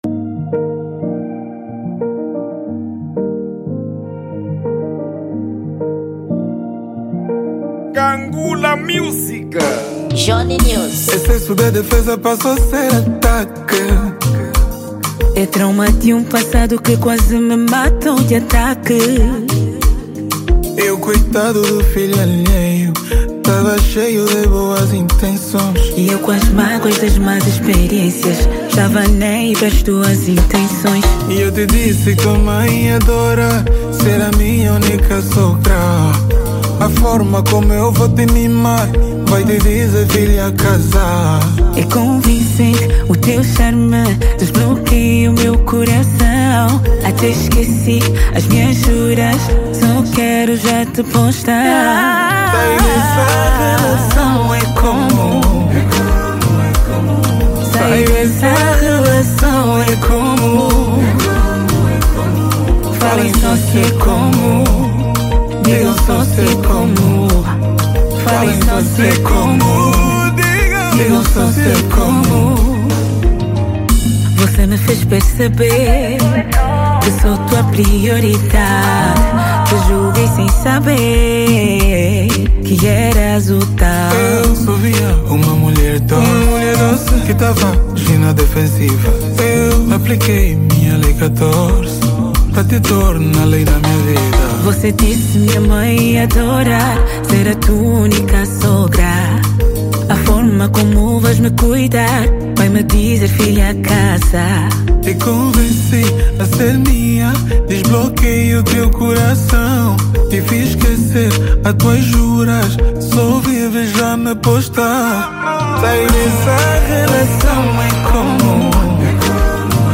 | Kizomba